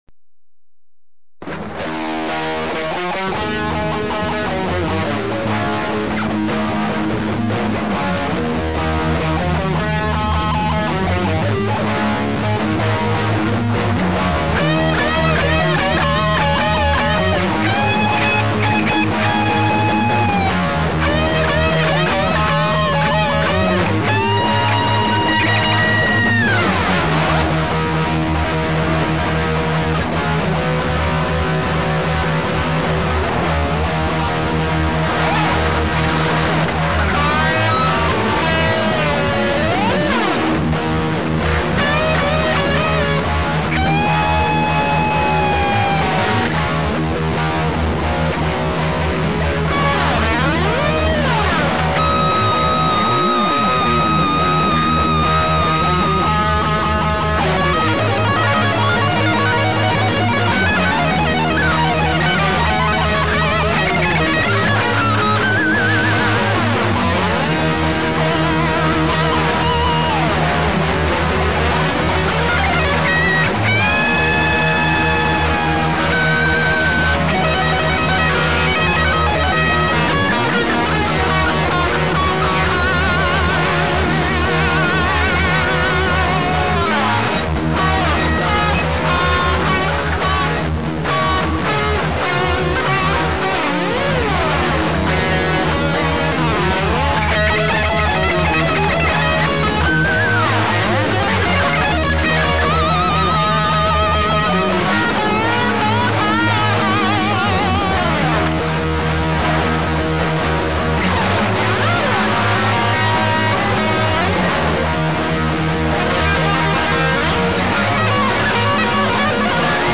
はっきり言って雑音です。 (MP3､完成度は無茶苦茶低いです)